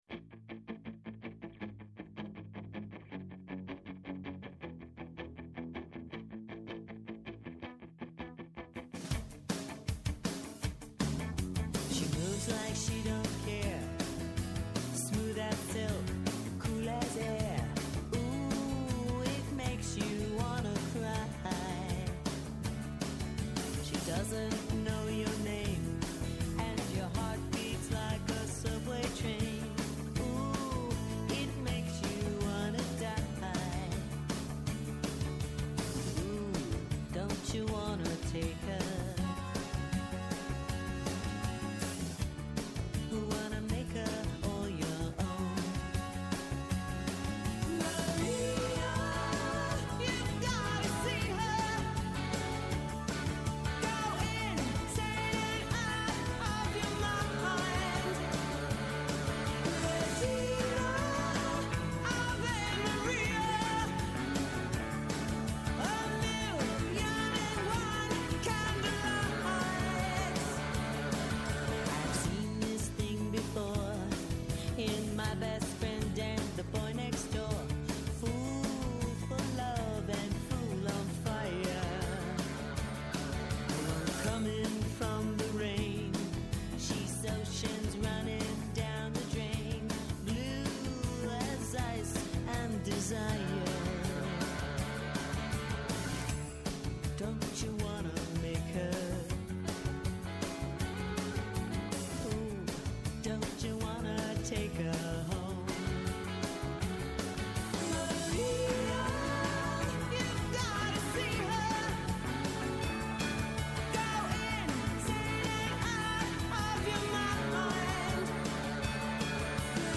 Καλεσμένος ο Αλέξανδρος Κόπτσης, Γενικός Γραμματέας Υπουργείου Παιδείας.